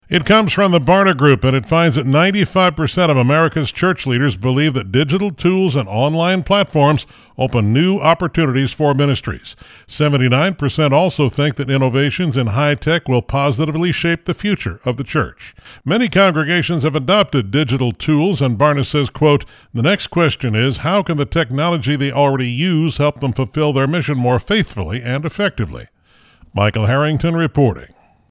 Salem Radio Network News Wednesday, April 15, 2026